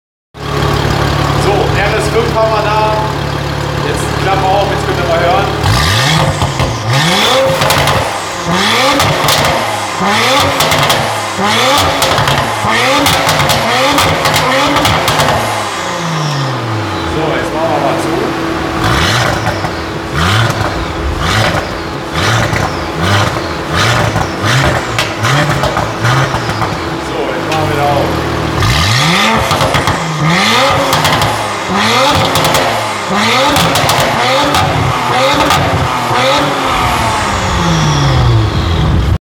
💥Audi RS5 Armageddon Sound💥 by sound effects free download